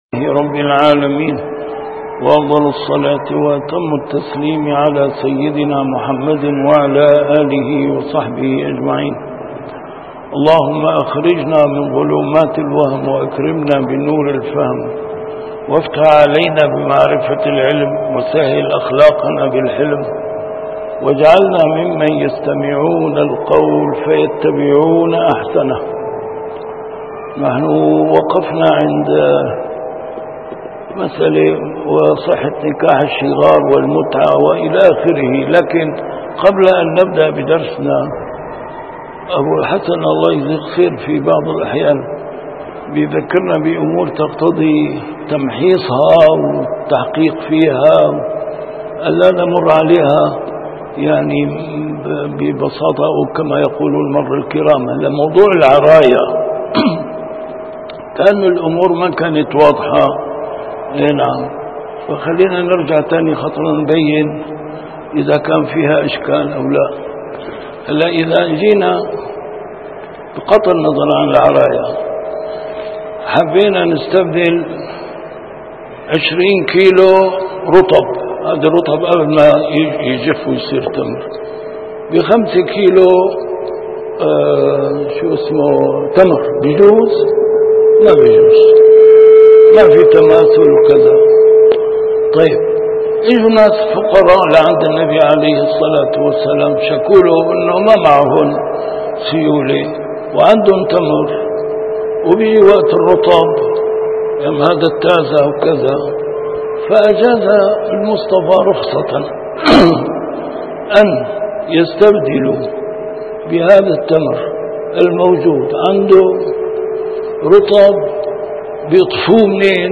A MARTYR SCHOLAR: IMAM MUHAMMAD SAEED RAMADAN AL-BOUTI - الدروس العلمية - كتاب الأشباه والنظائر للإمام السيوطي - كتاب الأشباه والنظائر، الدرس التاسع والستون: في قواعد كلية يتخرج عليها ما لا ينحصر من الصور الجزئية